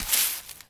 Broom Sweeping
sweeping_broom_leaves_02.wav